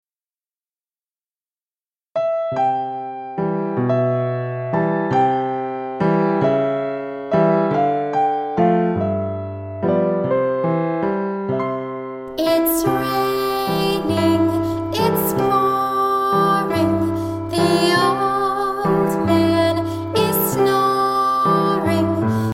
Vocal Song